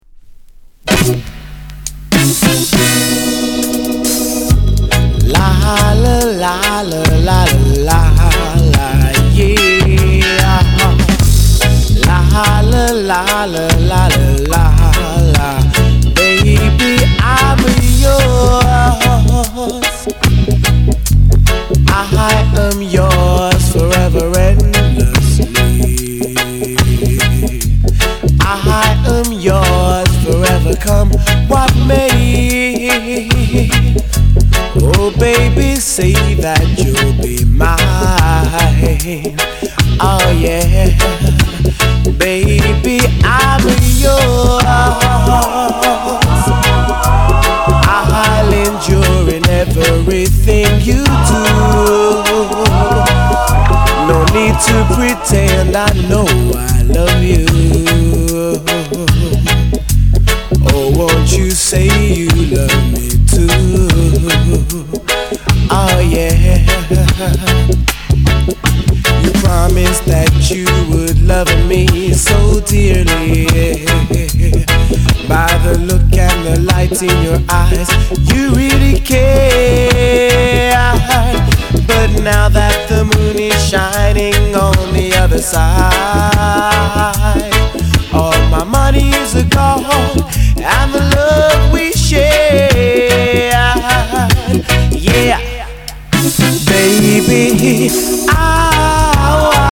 Genre: Reggae/Lovers Rock